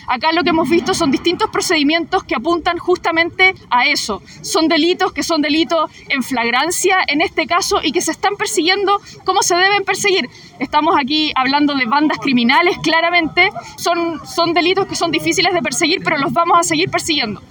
La delegada presidencial del Bío Bío, Daniela Dresdner, condenó los hechos ocurridos en la Provincia de Arauco y dijo que la persecución del robo de madera es compleja, debido a la participación de este tipo de bandas criminales organizadas.